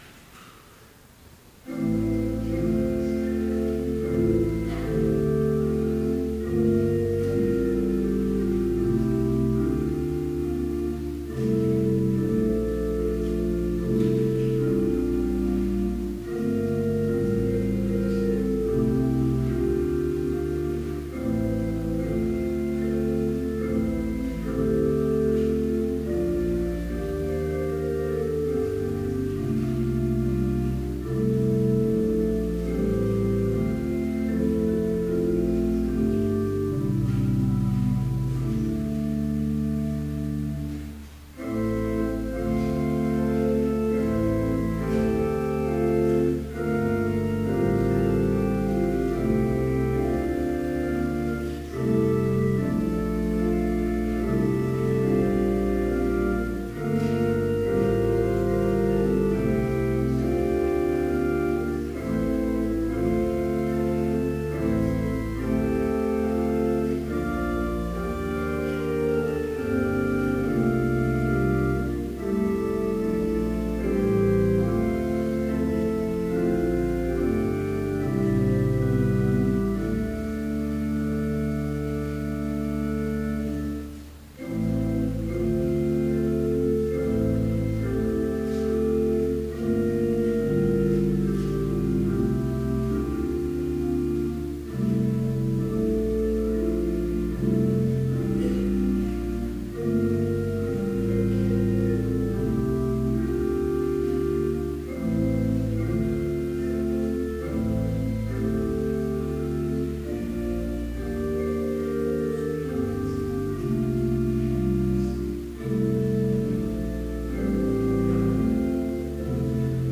Vespers in Trinity Chapel, Bethany Lutheran College, on November 20, 2013, (audio available) with None Specified preaching.
Complete service audio for Evening Vespers - November 20, 2013